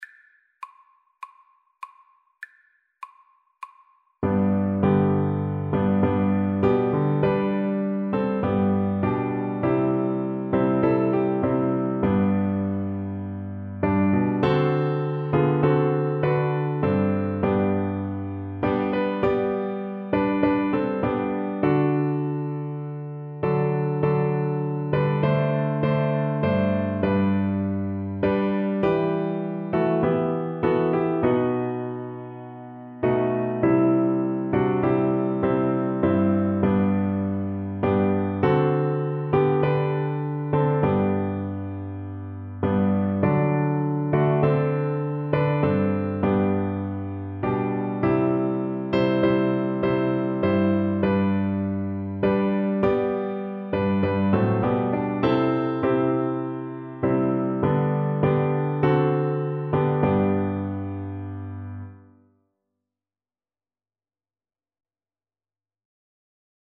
Christian Christian Cello Sheet Music I Love to Tell the Story
Cello
4/4 (View more 4/4 Music)
G major (Sounding Pitch) (View more G major Music for Cello )
Classical (View more Classical Cello Music)